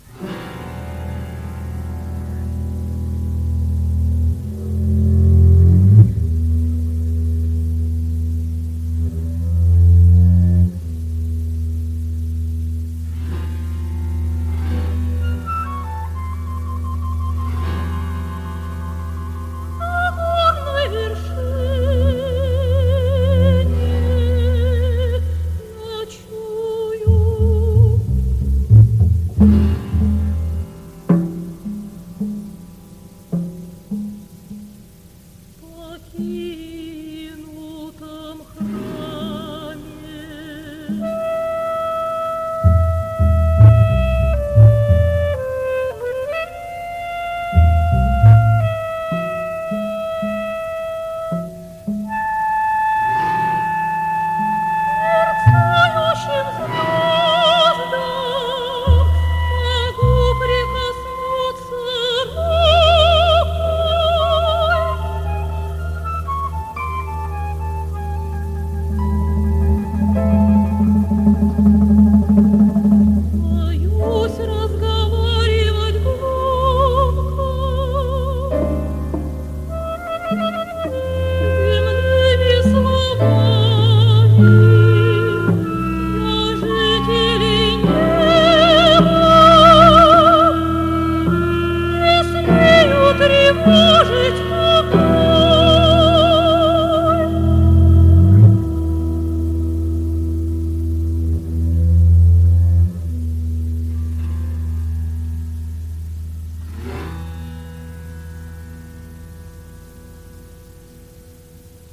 сопрано
флейта
кларнет